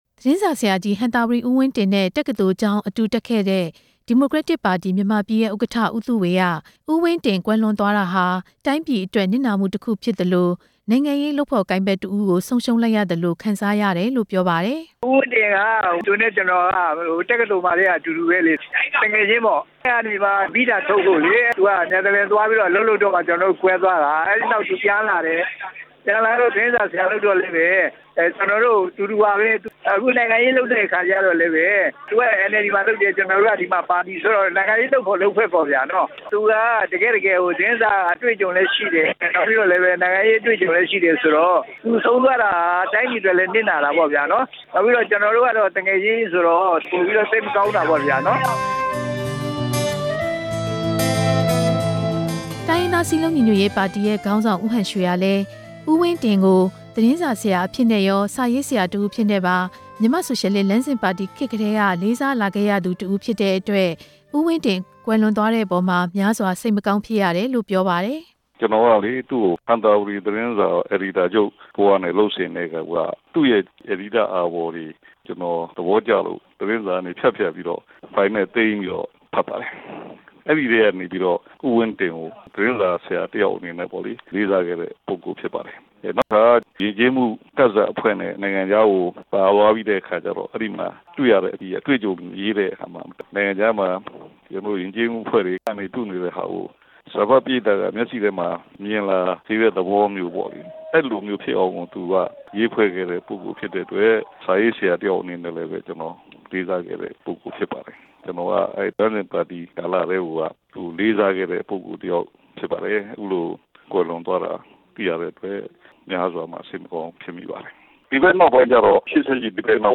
တိုင်းရင်းသားခေါင်းဆောင်တွေနဲ့ နိုင်ငံရေး လုပ်ဖော်ကိုင်ဖက်တွေရဲ့ ပြောပြချက်